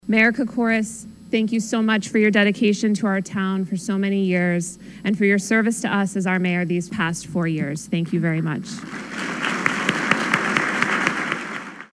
Joyce began her remarks by acknowledging her predecessor, Charles Kokoros.